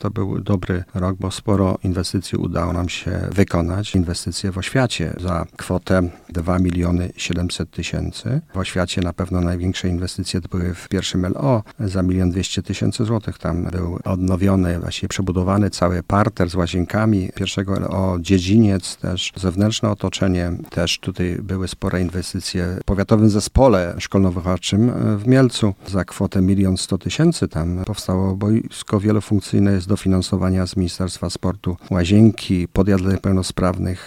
Kończący się rok 2021 bardzo dobrze został wykorzystany inwestycyjnie w oświacie, tak mówi starosta powiatu mieleckiego Stanisław Lonczak.